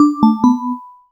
SubAnnouncement.wav